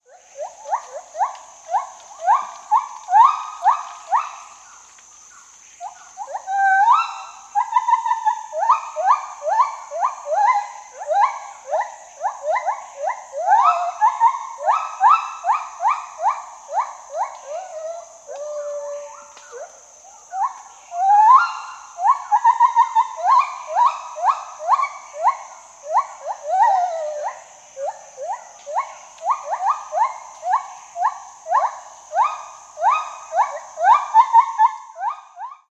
Звуки гиббона: Гиббон в дикой природе среди деревьев